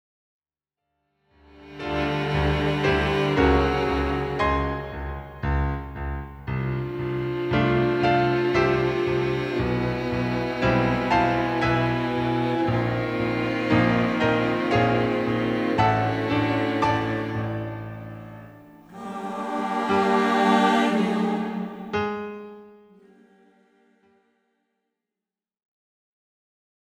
) sont servis par des instruments à cordes et piano.